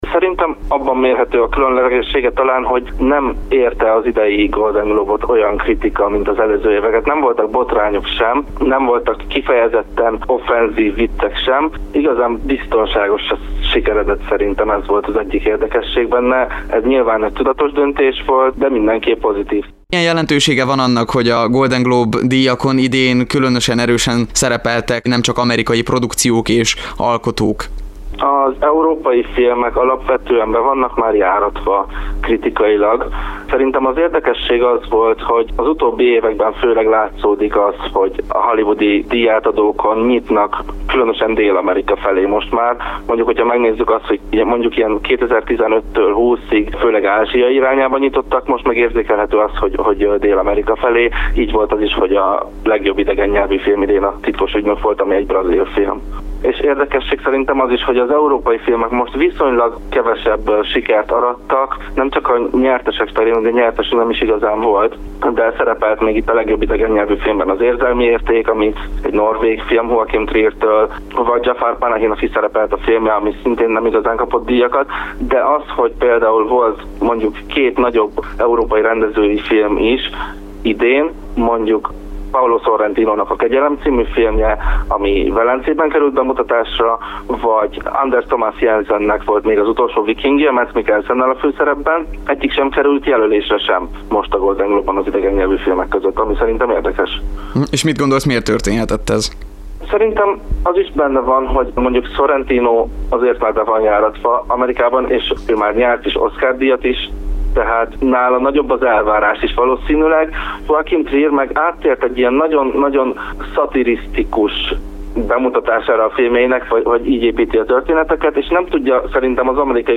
A kiemelt figyelemmel bíró és díjazott alkotásokról és alkotókról, illetve a díjátadó érdekességeiről kérdezte